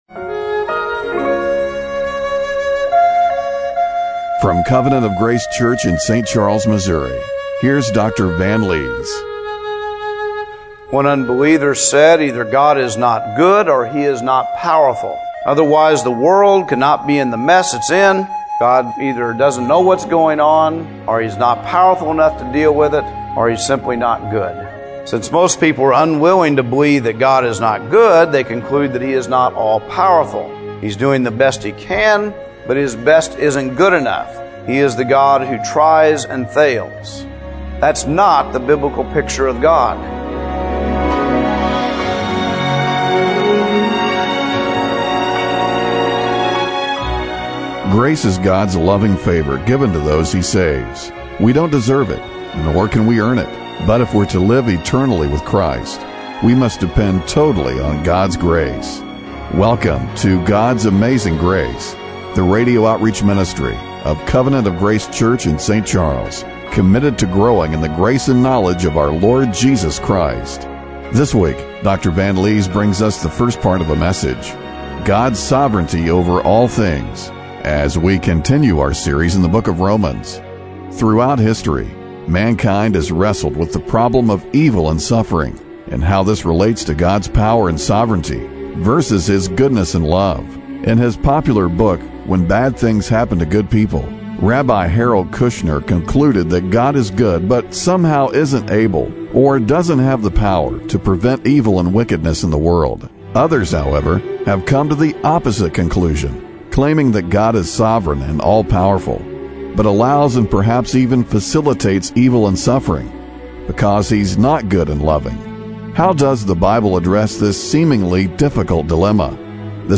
Romans 11:33-36 Service Type: Radio Broadcast How does the Bible address the difficult dilemma involved with God's sovereignty?